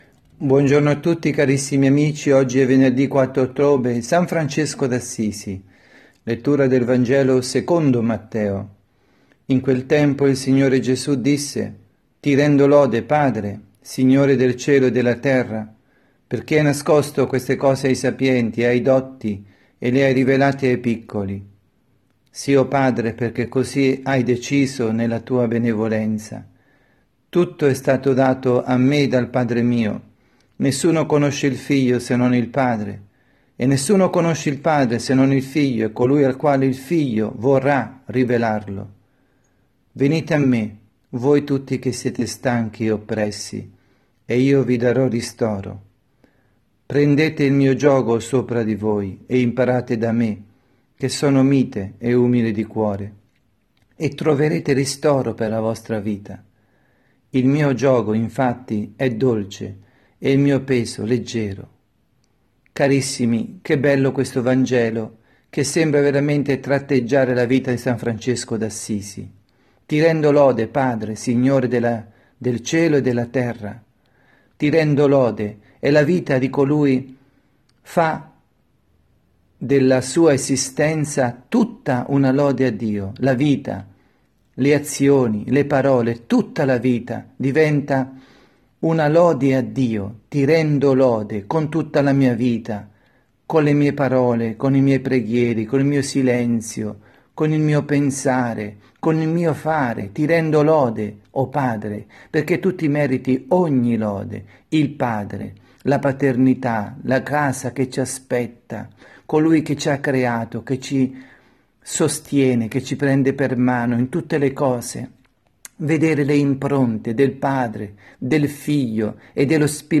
2019-10-04_Venerdi_pMG_Omelia_dalla_Casa_di_Riposo_S_Marta_Milano